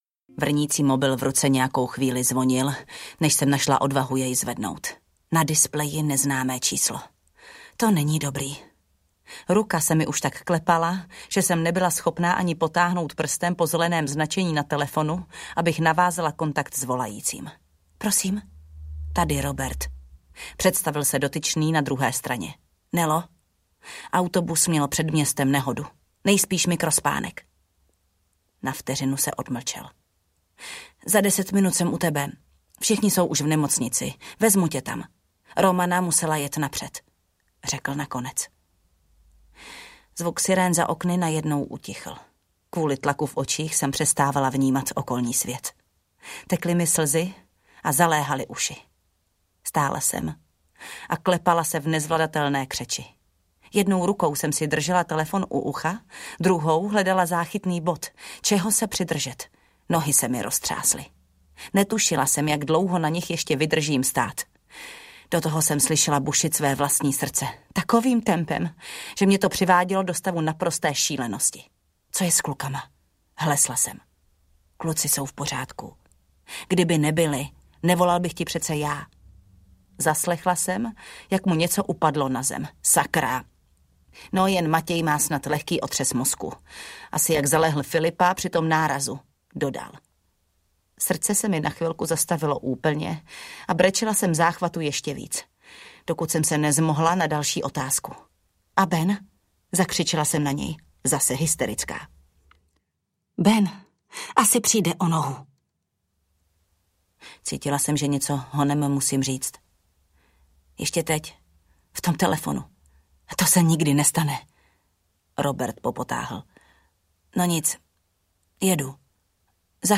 Hra na druhou audiokniha
Ukázka z knihy
• InterpretJana Stryková